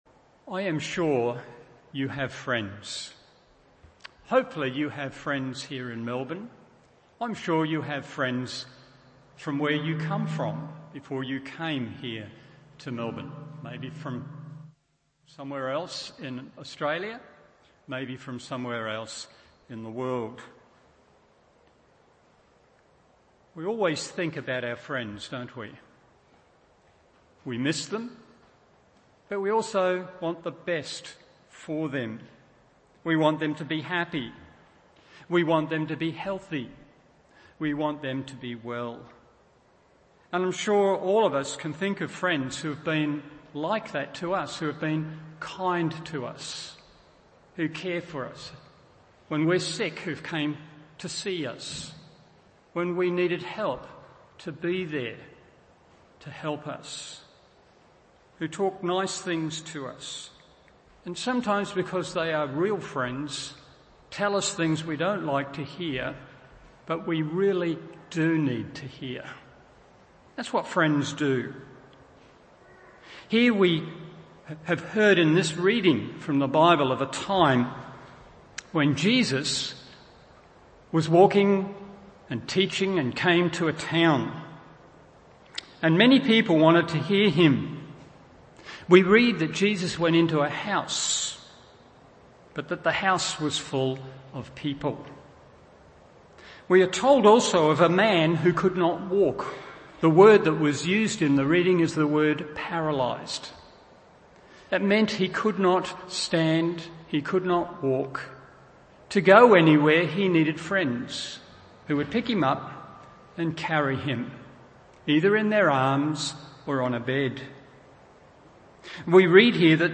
Evening Service Mark 2:1-12…